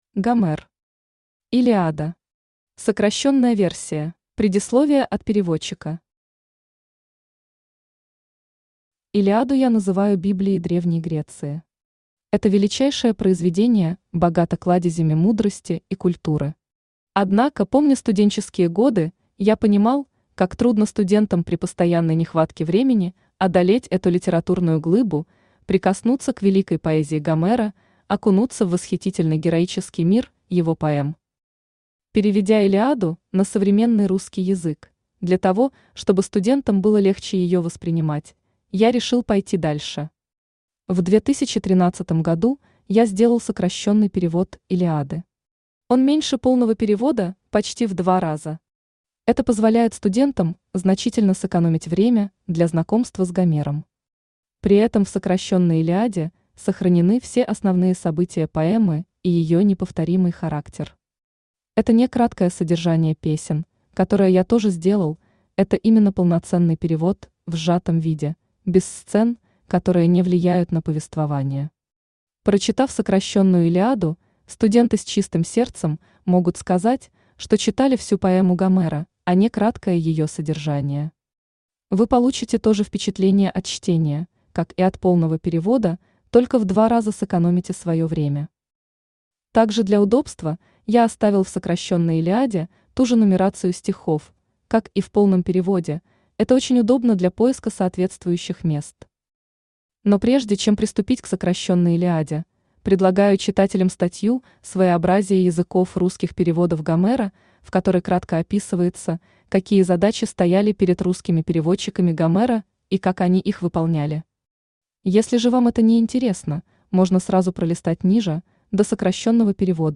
Читает: Авточтец ЛитРес
Аудиокнига «Илиада. Сокращённая версия». Автор - Гомер.